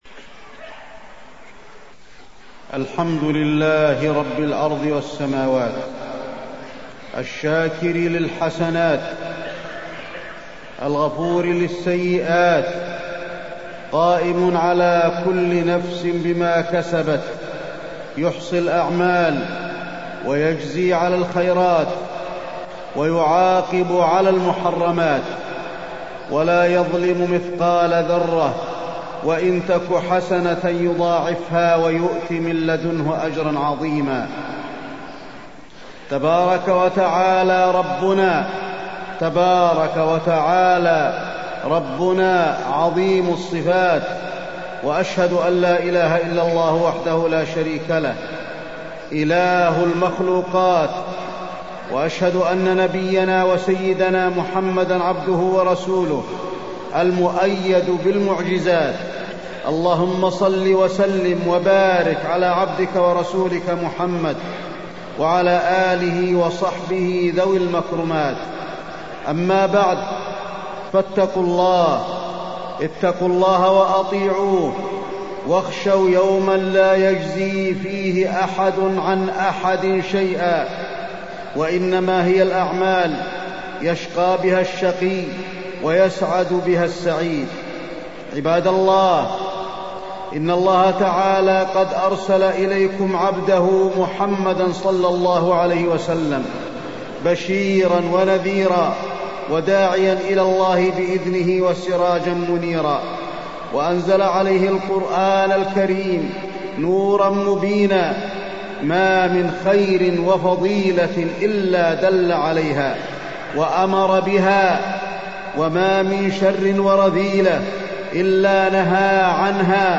تاريخ النشر ١٧ ذو القعدة ١٤٢٤ هـ المكان: المسجد النبوي الشيخ: فضيلة الشيخ د. علي بن عبدالرحمن الحذيفي فضيلة الشيخ د. علي بن عبدالرحمن الحذيفي محاسبة النفس